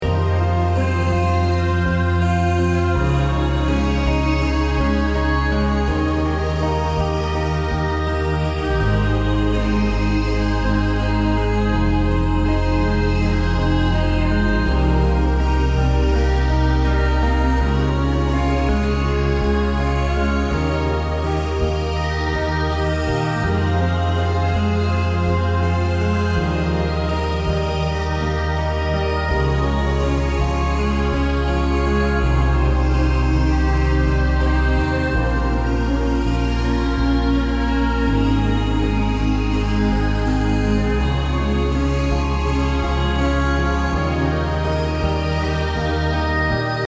528hz BPM80-89 calm Healing Instrument インストルメント 癒し 穏やか
BPM 82